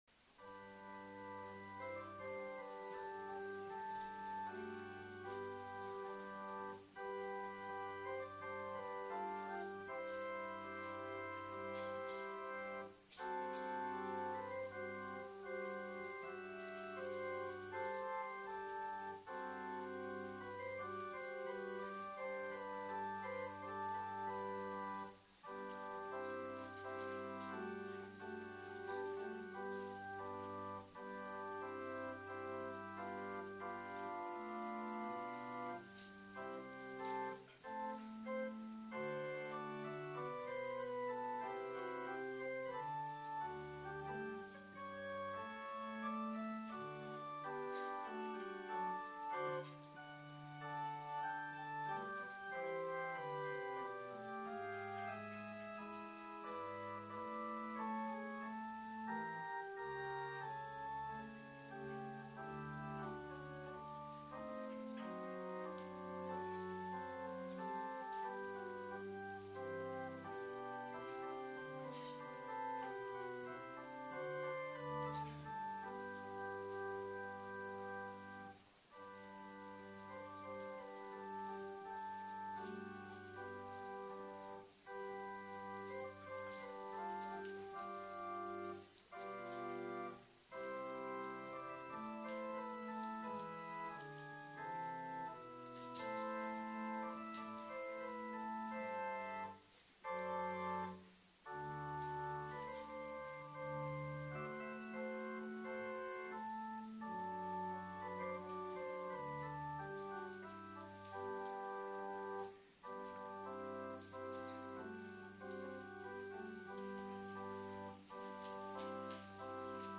12月24日（水）、クリスマス・イヴ礼拝 クリスマス・イヴ礼拝の音声 12月25日（木）は、主の御降誕を覚える日。